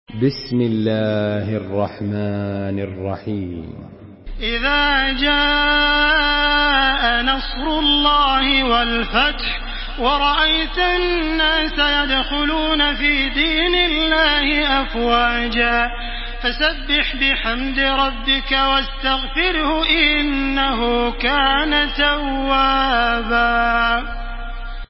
Surah An-Nasr MP3 in the Voice of Makkah Taraweeh 1434 in Hafs Narration
Murattal